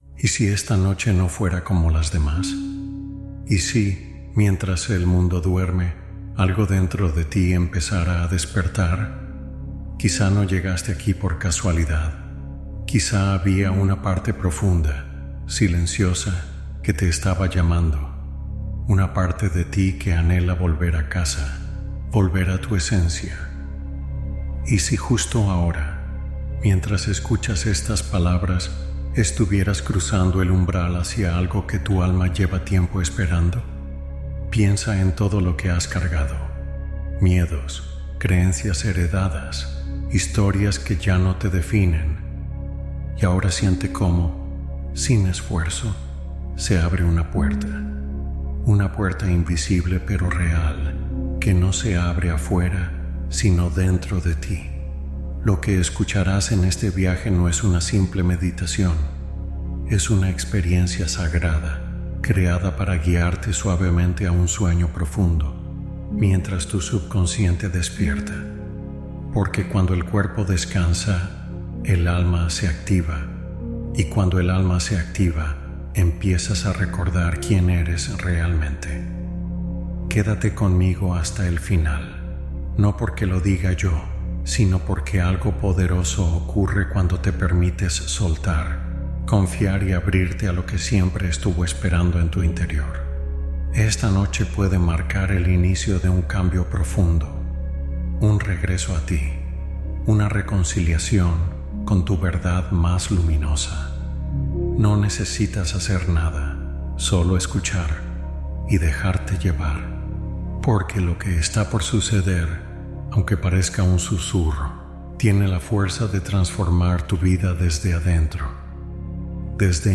Hipnosis de conexión interior para escucha personal y orientación interna